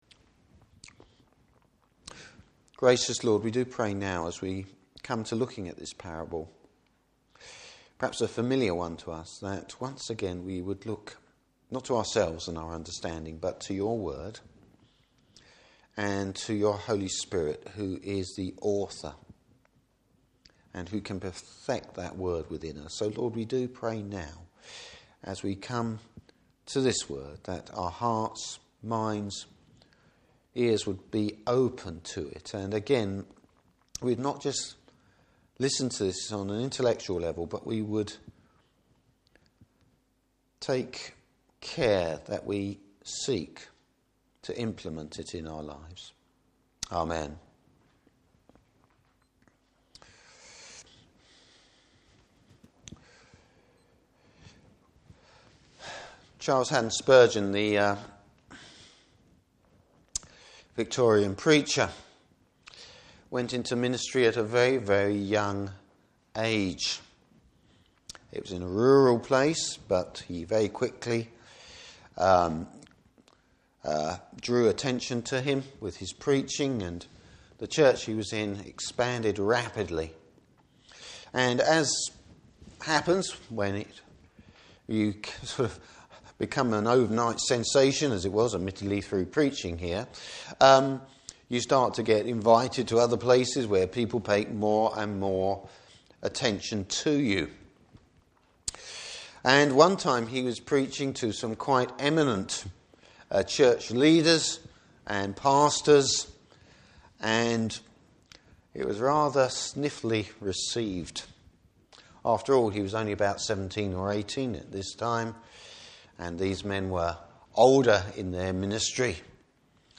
Service Type: Morning Service Bible Text: Luke 20:1-19.